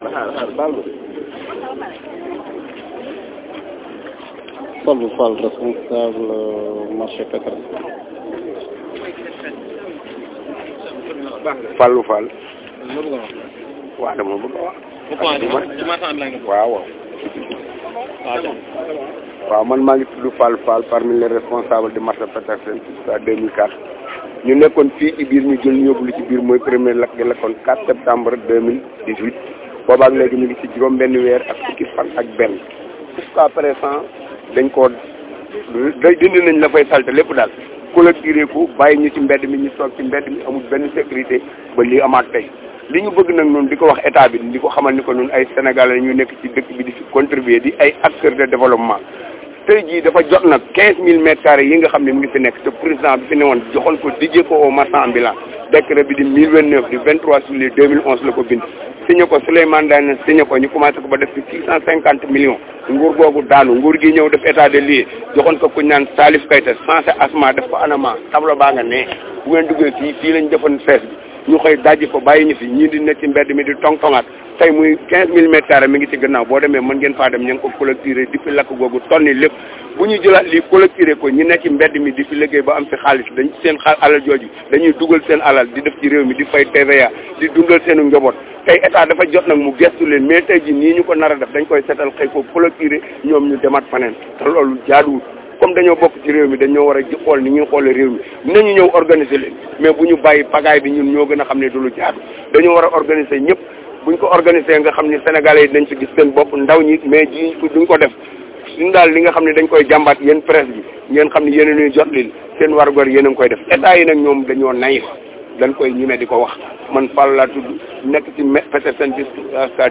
Très en colère, il interpelle les autorités étatiques à prendre des mesures sérieuses avant que le pire ne se produise.